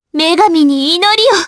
Frey-Vox_Skill6_jp_b.wav